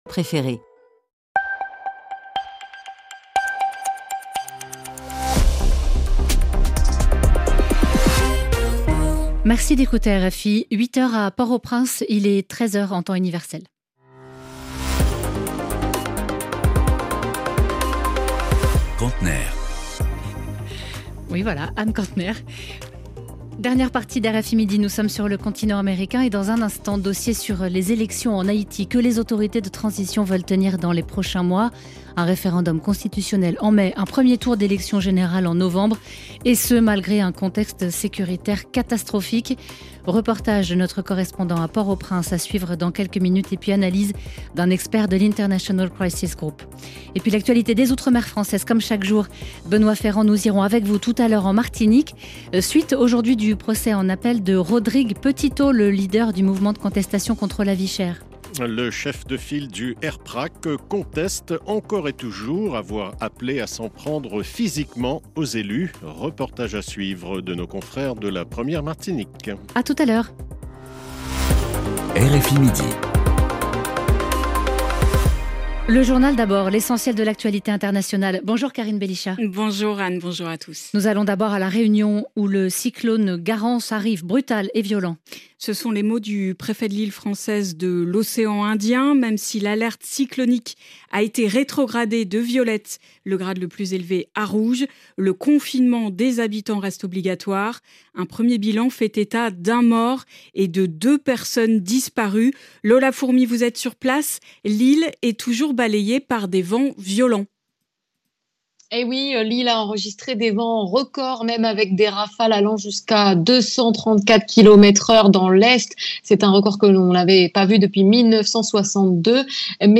C’est pour cet auditoire que, chaque jour, RFI consacre un reportage, ou une interview, spécifiquement consacré à Haïti.